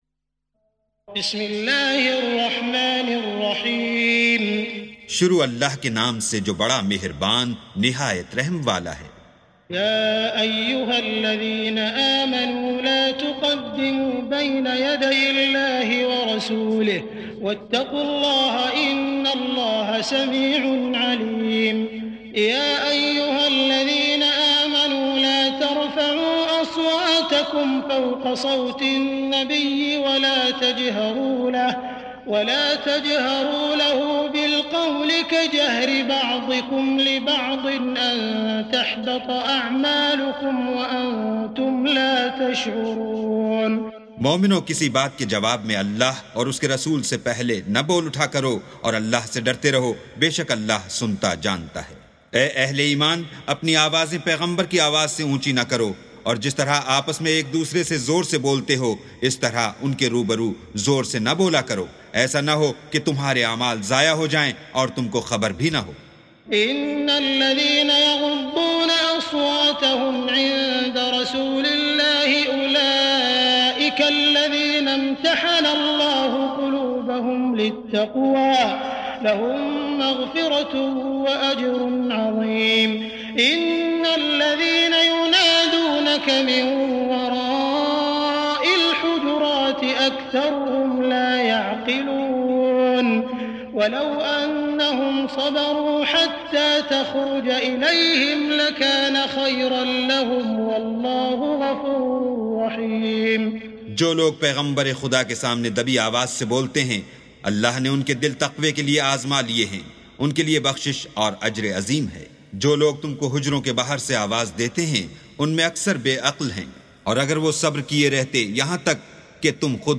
سُورَةُ الحِجۡرِات بصوت الشيخ السديس والشريم مترجم إلى الاردو